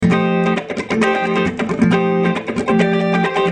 Free MP3 funk music guitars loops & sounds 3
guitar loop - funk 44